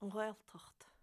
Rather than a "w" with the lips, try to make a gentle "u" in the throat.